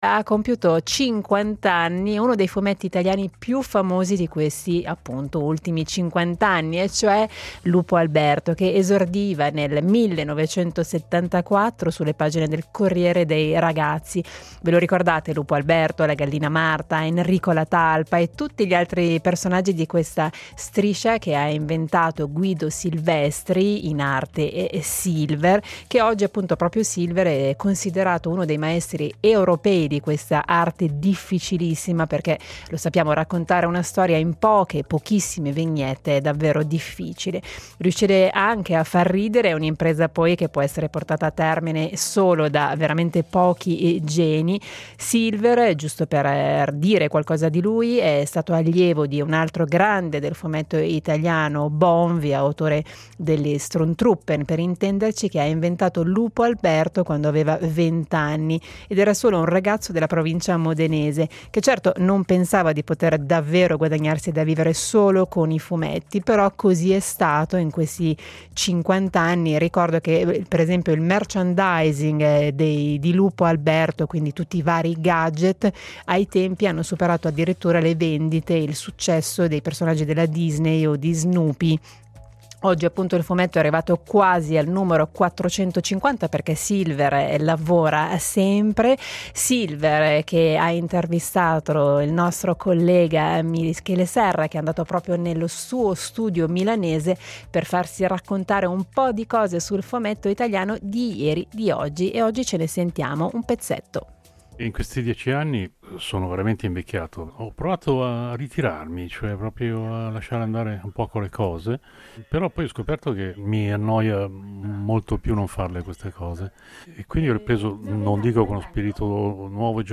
Silver Contenuto audio Disponibile su Scarica Ha compiuto 50 anni uno dei fumetti italiani più famosi: Lupo Alberto. Michele Serra ha intervistato il suo creatore, Silver, per farsi raccontare un po’ di cose sul fumetto italiano di ieri e di oggi.